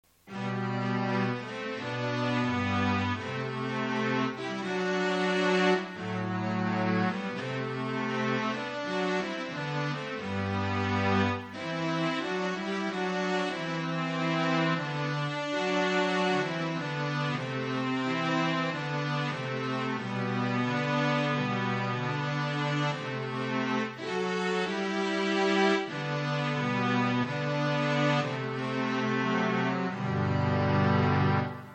Here are a couple of tunes played on it:-